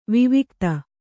♪ vivikta